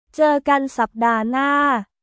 ジュー・ガン・サップダー・ナー